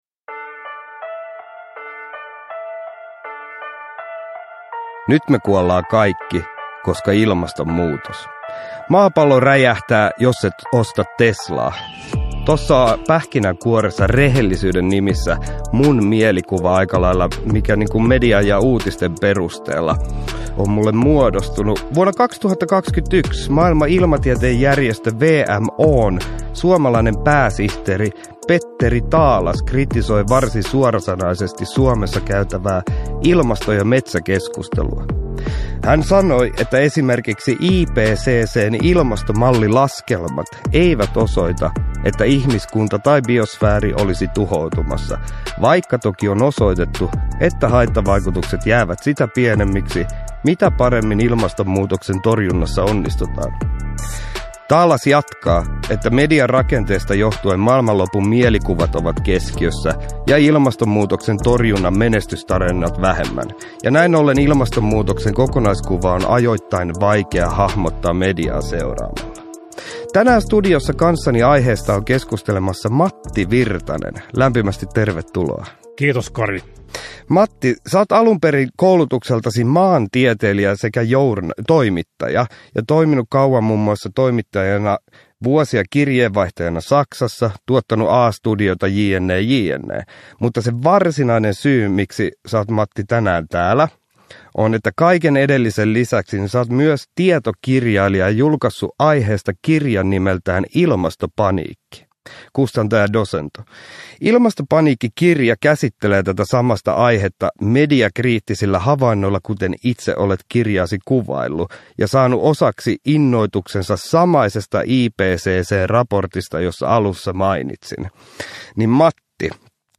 K1/J6 Nyt me kuollaan kaikki... (ljudbok) av Kari Hautamäki